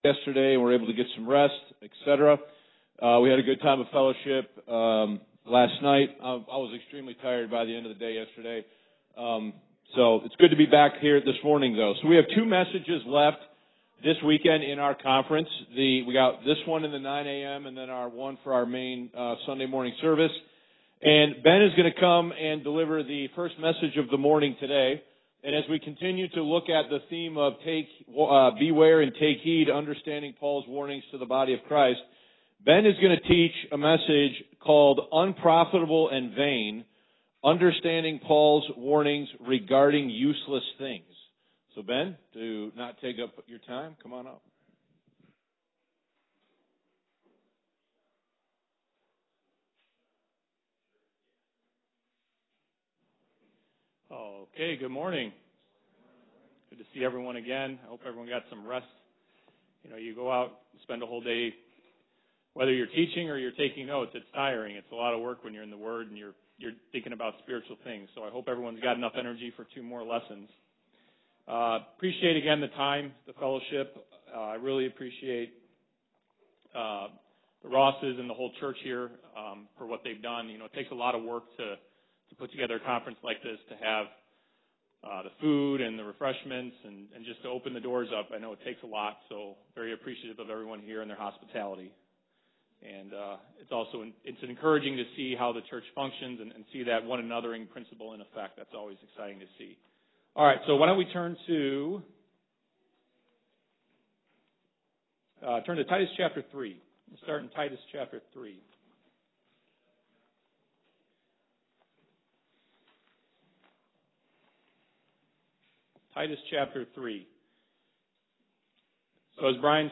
2025 West Michigan Grace Bible Conference | Beware & Take Heed: Understanding Paul’s Warnings To The Body of Christ